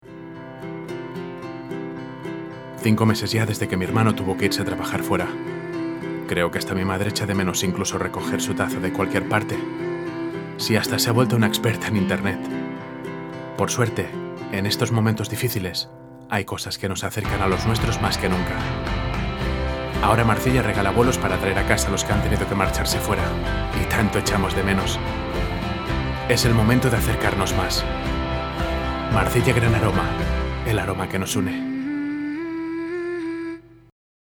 Voz masculina adulto joven. Timbre grabe, dulce y profundo. Posee una musicalidad sensual y atractiva, cálida, próxima, segura y natural
Sprechprobe: Industrie (Muttersprache):